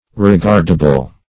Search Result for " regardable" : The Collaborative International Dictionary of English v.0.48: Regardable \Re*gard"a*ble\ (-?*b'l), a. Worthy of regard or notice; to be regarded; observable.